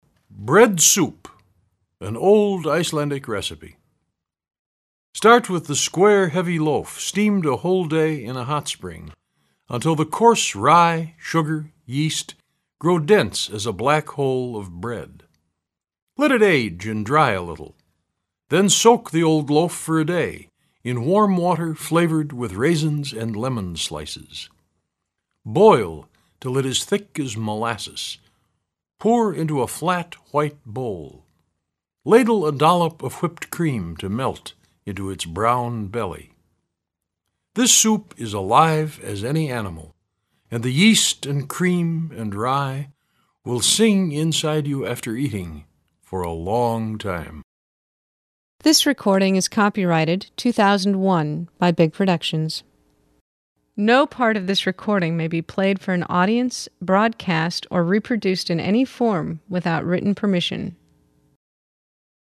BREAD-SOUP-Mono-Web.mp3